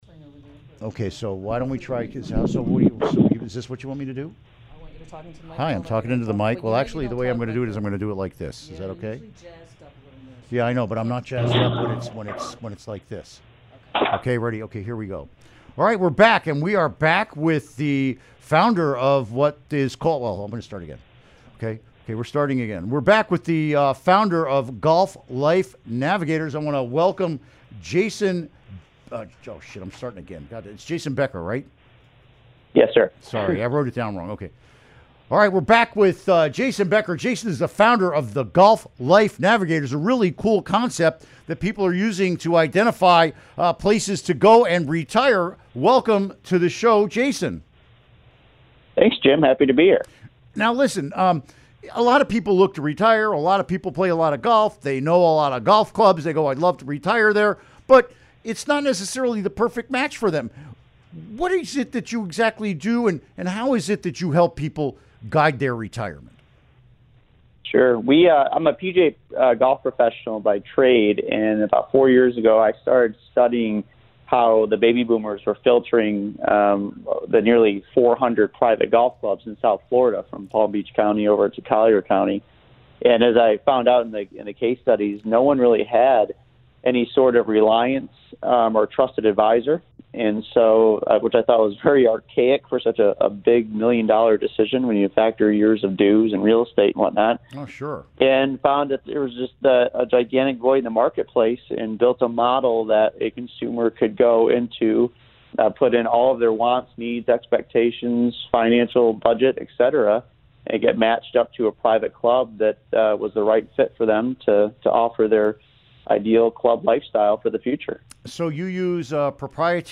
Interview Segment Click here to download (To download, right-click and select “Save Link As”.)